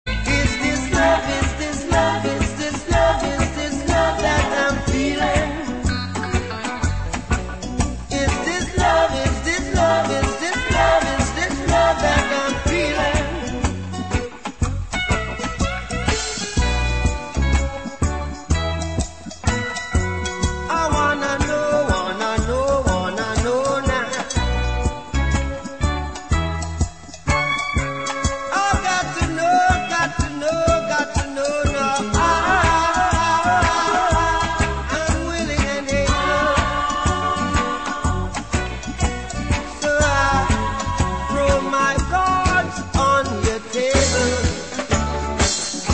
• Reggae Ringtones